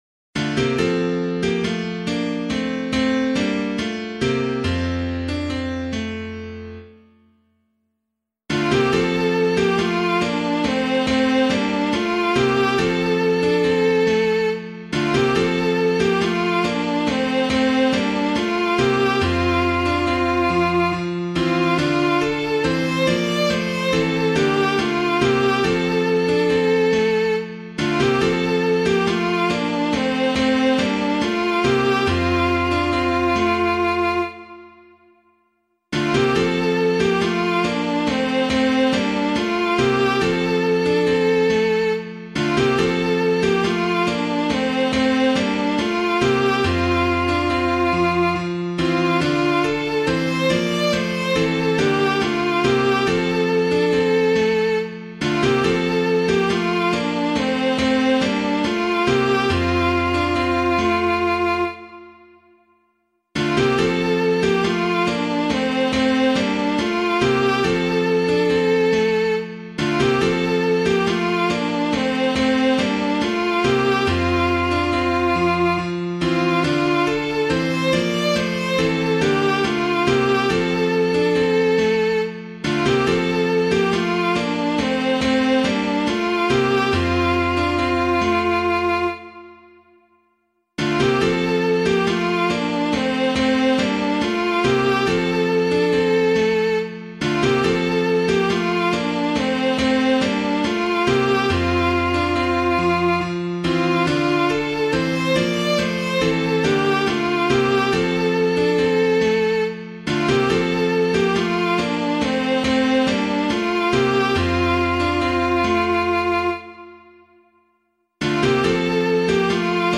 Hymn of the Day:  The Epiphany of the Lord
Composer:    traditional Irish folk song;
keyboard accompaniment
piano